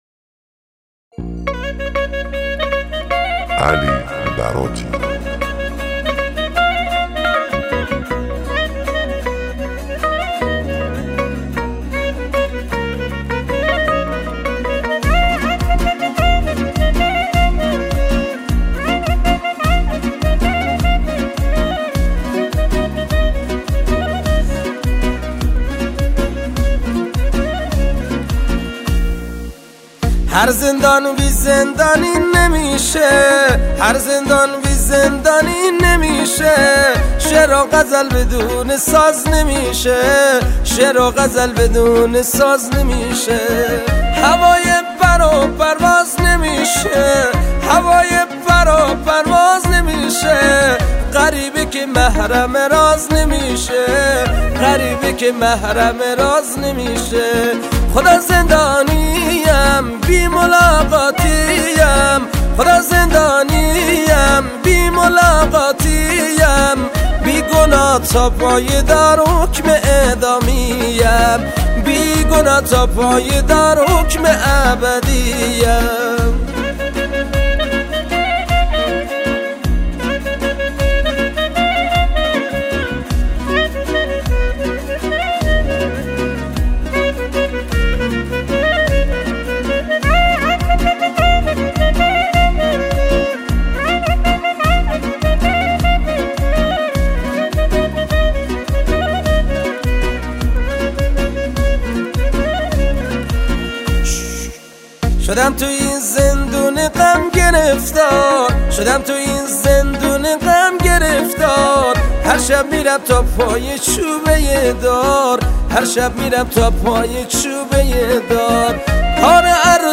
بخش دانلود آهنگ غمگین آرشیو